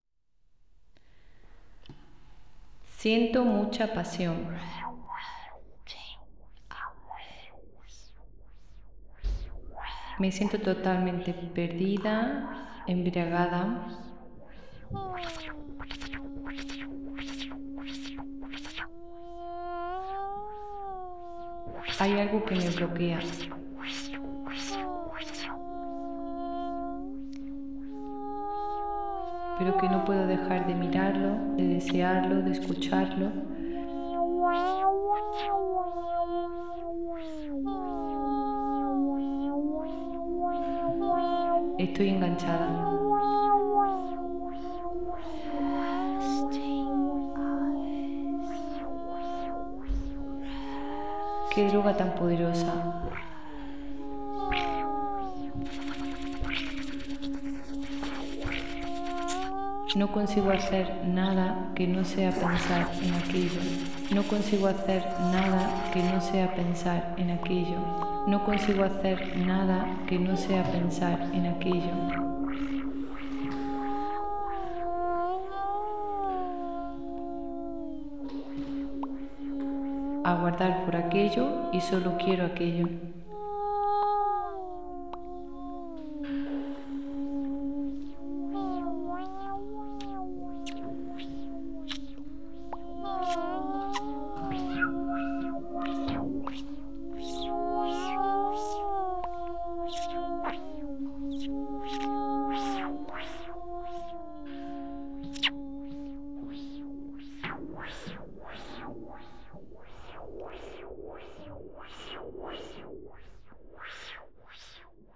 Try to reproduce it with your voice and record them, so you can ear them how many times you want.
Each person of the group used the sample with all the sounds recorded and do it owns mix.
This is my mix to my proposal.
en Bodynoise